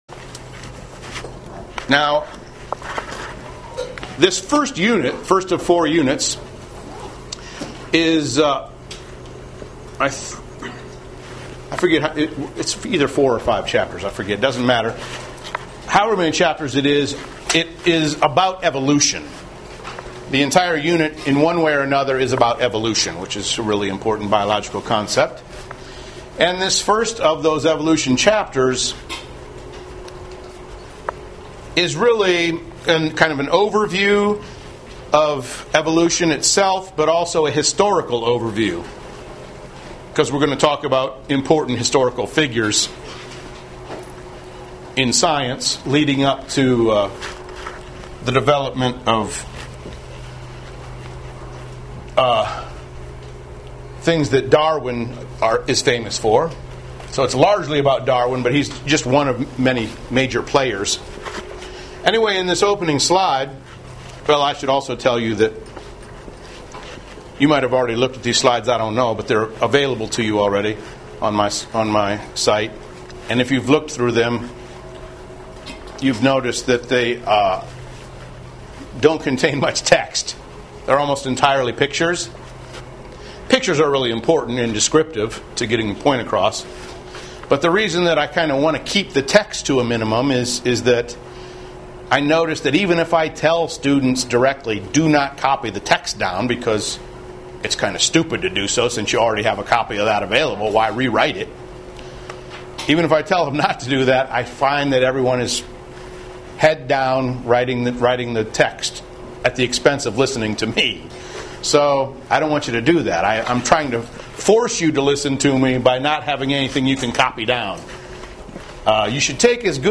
Lectures and slide shows for cell biology courses